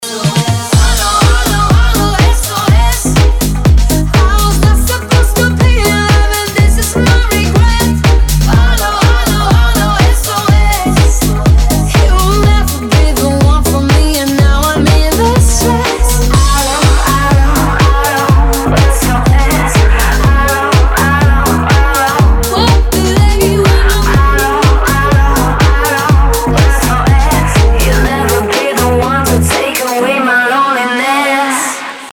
из Танцевальные